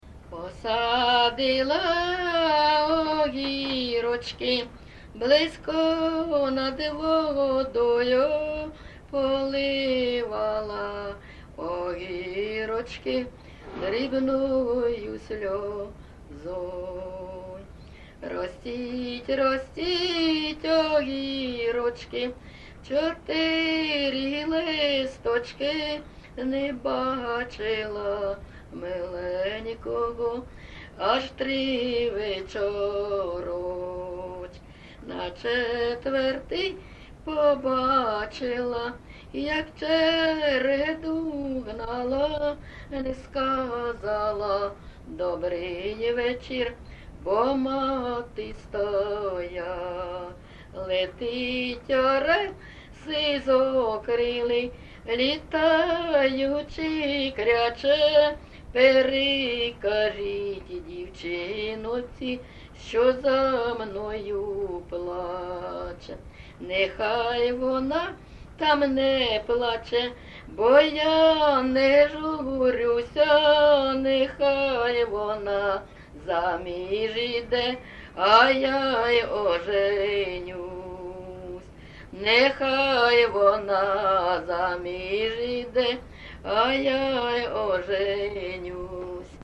ЖанрПісні з особистого та родинного життя
Місце записум. Маріуполь, Донецька обл., Україна, Північне Причорноморʼя